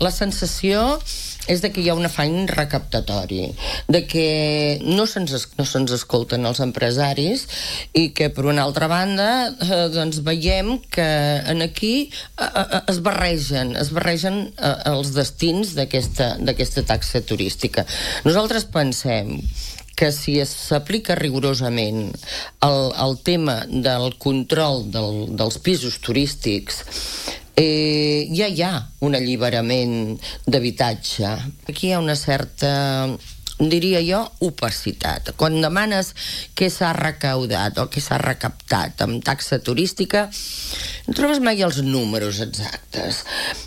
Ho ha explicat aquest dimecres en una entrevista al programa matinal de RCT, després de la visita del Gremi a la fira turística de Madrid.